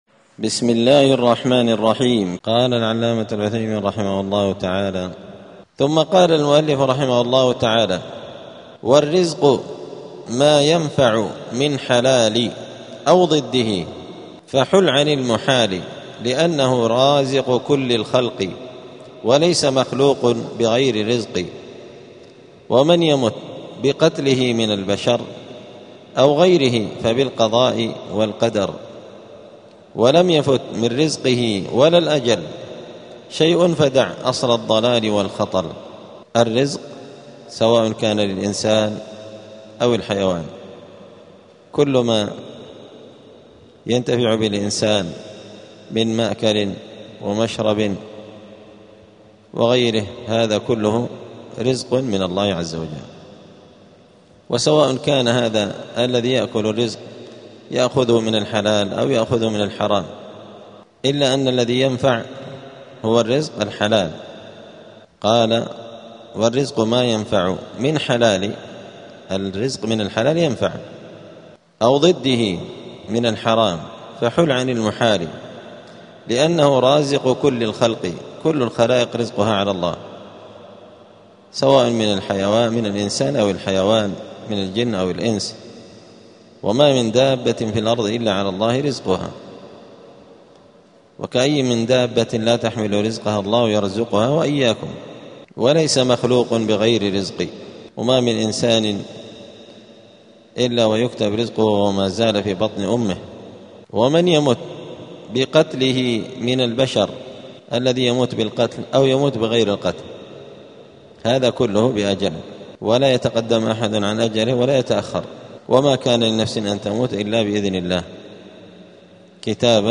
دار الحديث السلفية بمسجد الفرقان قشن المهرة اليمن
62الدرس-الثاني-والستون-من-شرح-العقيدة-السفارينية.mp3